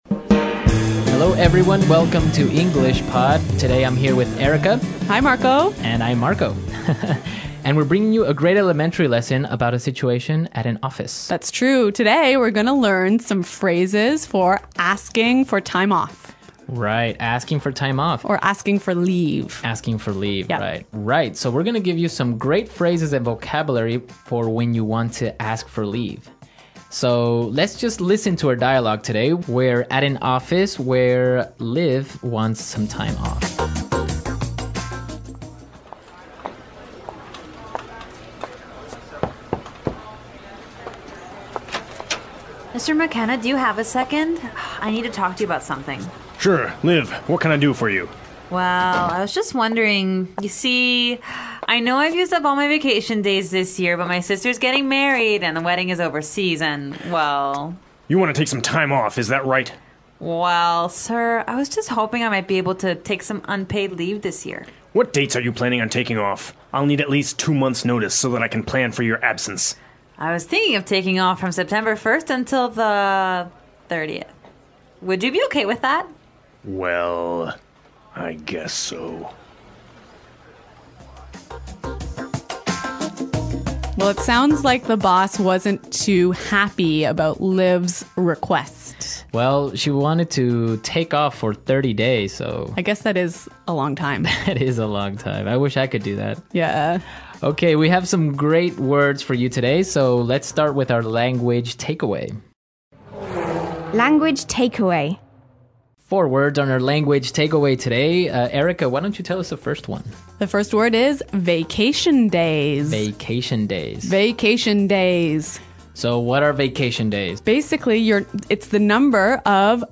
EnglishPod每一期的对话难度不同，不管你是什么英语水平，都可以利用这个材料练听力，因为它根据学习者的英语水平区分了个等级，即：